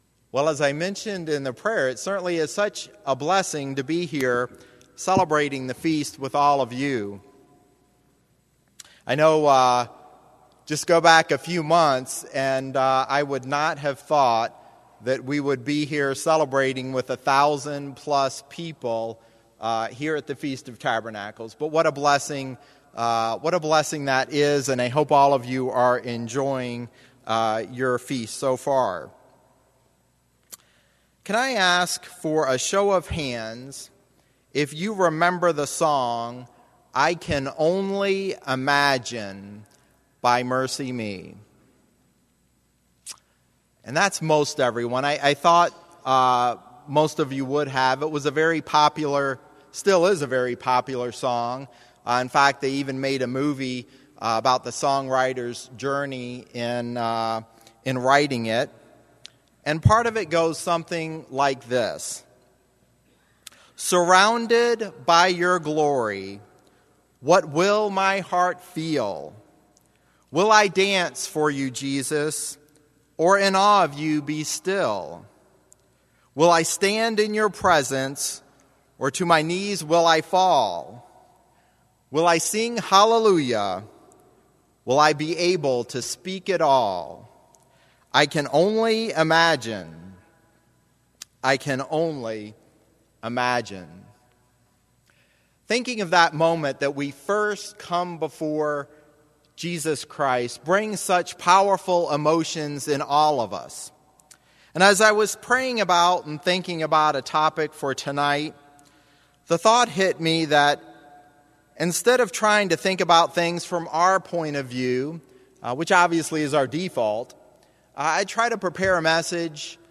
This sermon was given at the St. George, Utah 2020 Feast site.